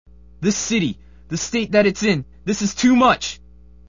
If you thought Resident Evil was the apex of bad voice acting, think again.